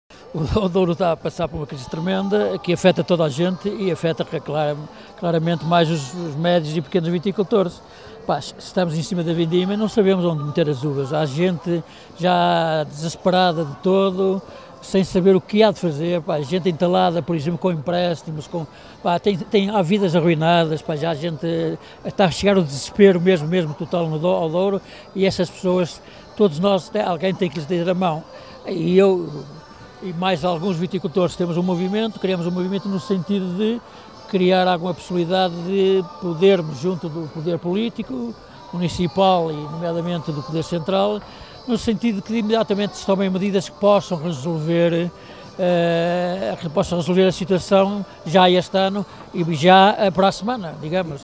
Na abertura da Feira da Maçã, Vinho e Azeite estiveram alguns viticultores de São João da Pesqueira para falar com o ministro da Agricultura.